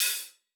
TC2 Live Hihat12.wav